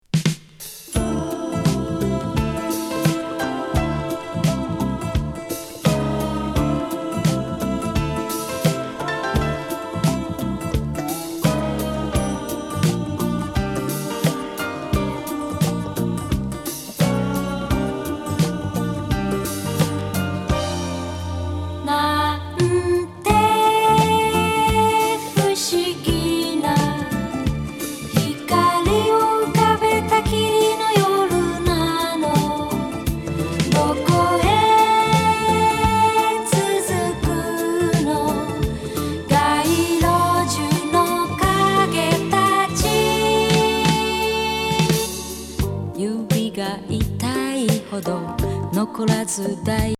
極上スムース＆メロウ